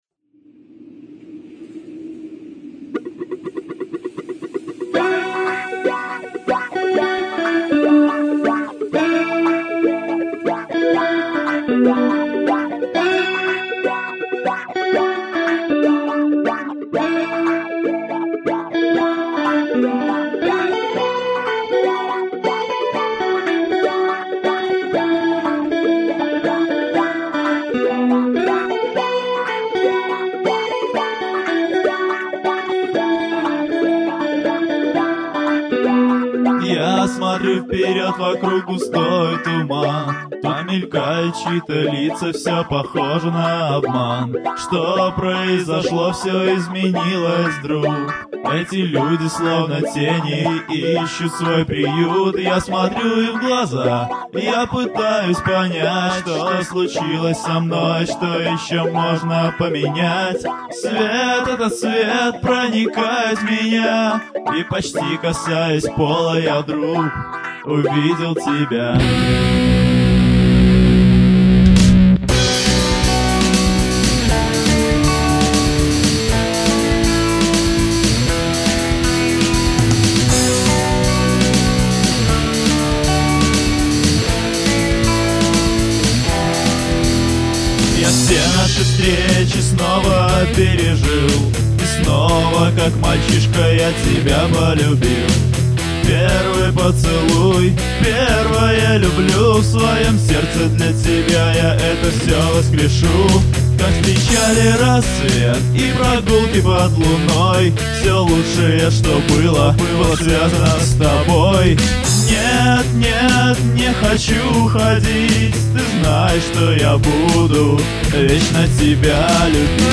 гитара, бас-гитара